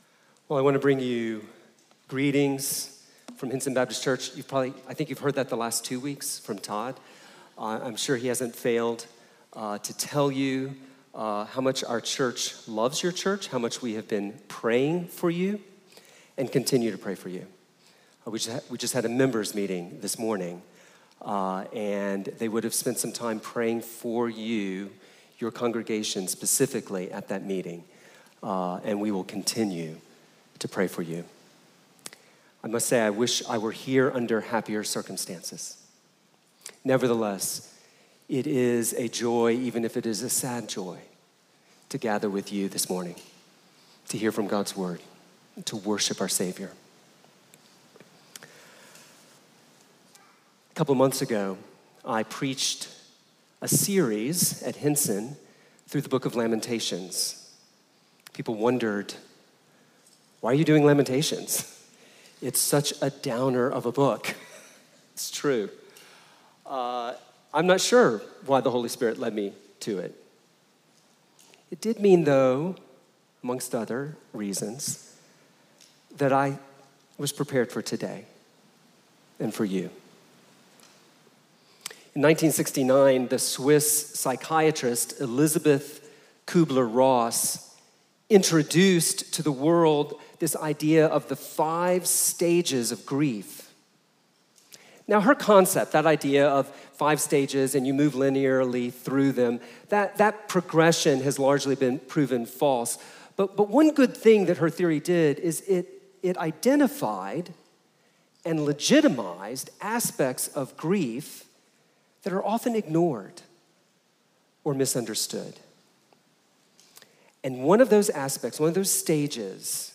Trinity Church Portland